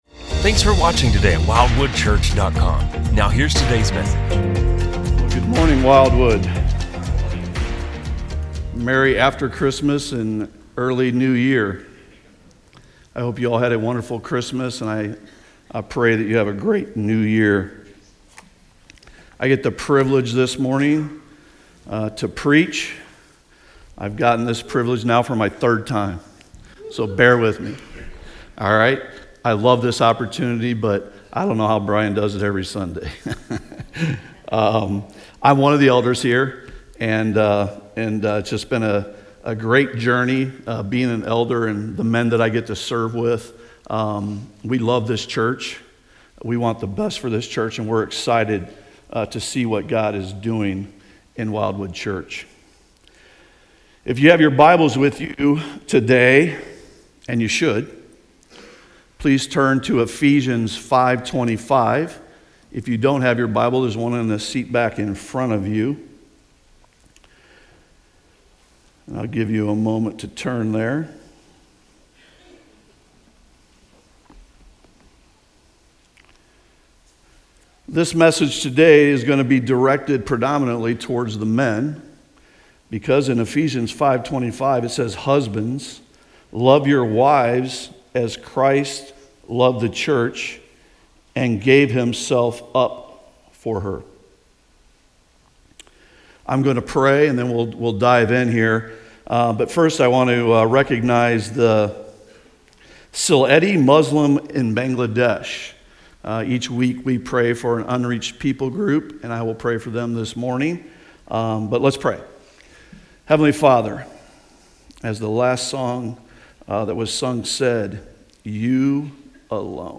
From Series: "Stand Alone Sermons"